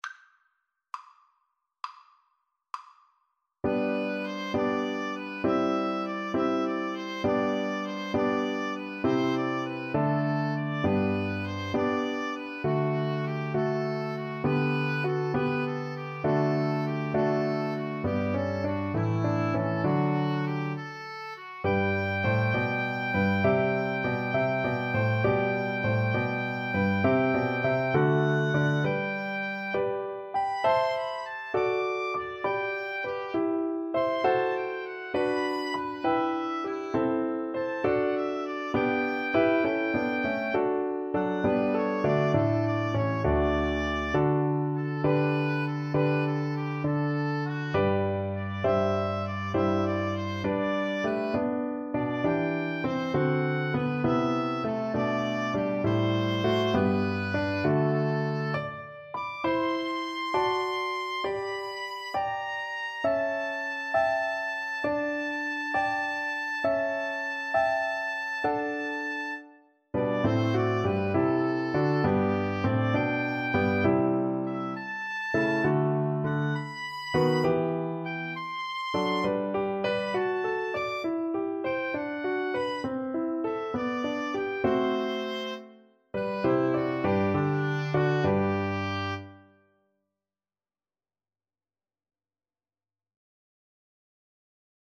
Largo
Classical (View more Classical Oboe-Violin Duet Music)